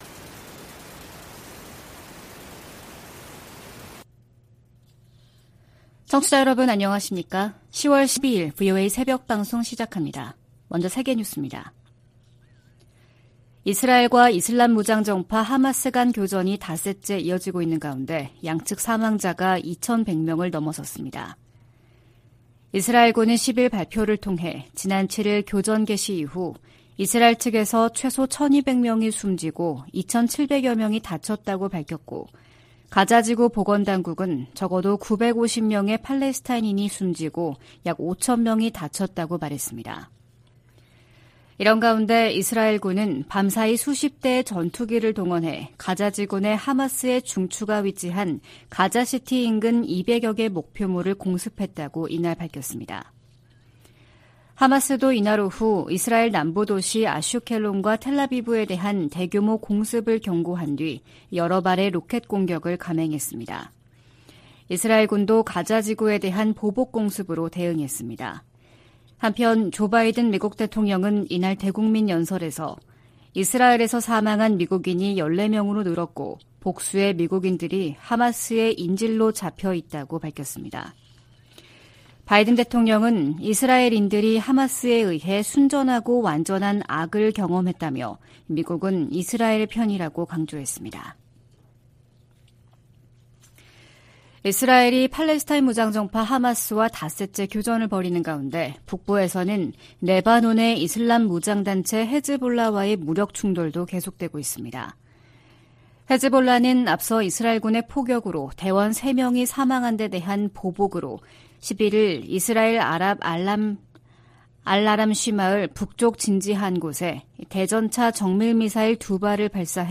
VOA 한국어 '출발 뉴스 쇼', 2023년 10월 12일 방송입니다. 조 바이든 미국 대통령이 이스라엘에 대한 하마스의 공격을 테러로 규정하고 이스라엘에 전폭적 지원을 약속했습니다. 한국 군 당국이 하마스의 이스라엘 공격 방식과 유사한 북한의 대남 공격 가능성에 대비하고 있다고 밝혔습니다. 미 국무부가 하마스와 북한 간 무기 거래 가능성과 관련해 어떤 나라도 하마스를 지원해선 안 된다고 강조했습니다.